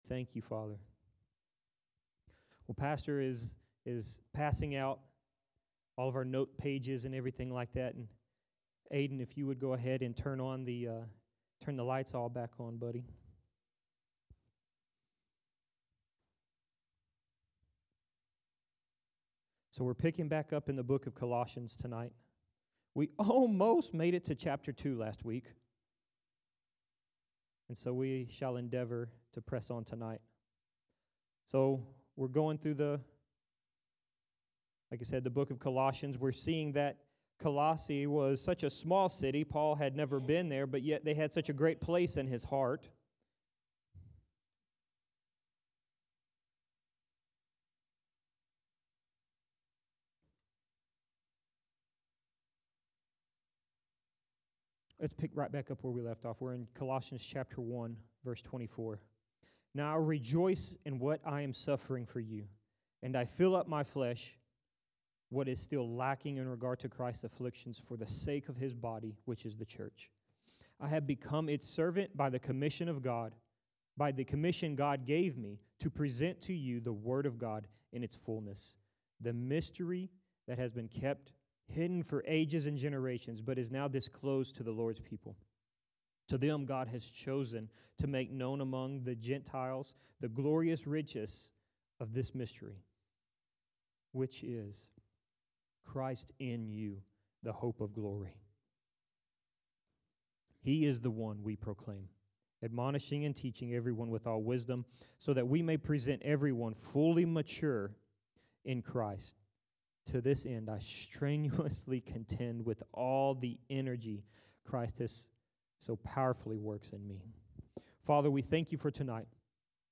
Sermons | Harvest Time Church